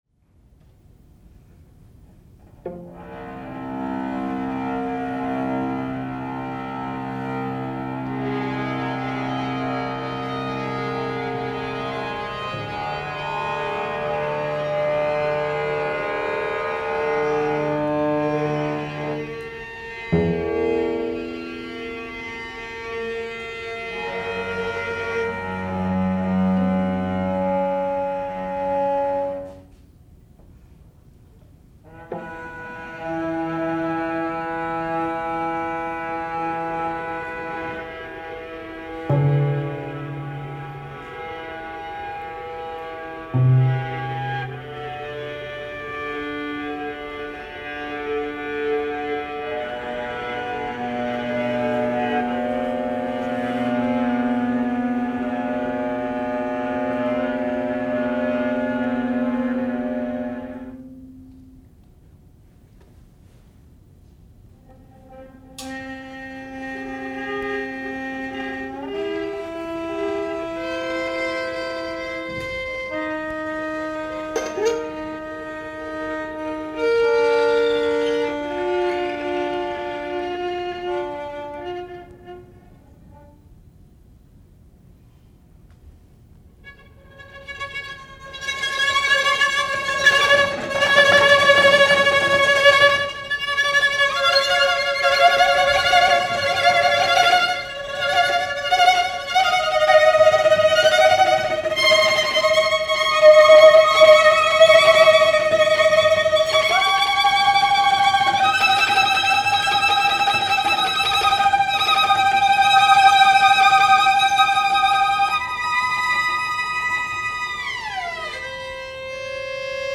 Composed for a concert of new short works
viola
‘cello
MP3 ofÂ first performance Duration: 5-6 minutes Year(s) of composition: Â 2005